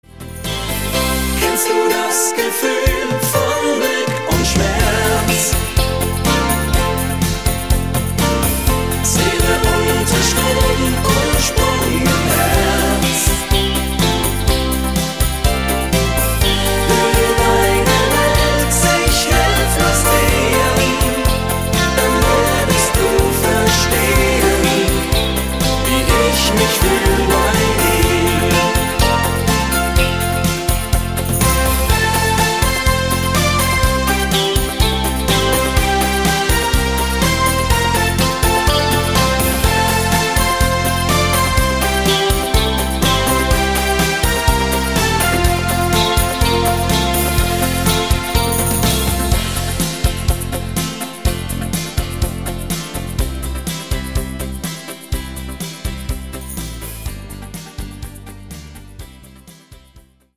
sehr melodiöser Schlager Download Buy
Rhythmus  Cha cha
Art  Deutsch, Schlager 2020er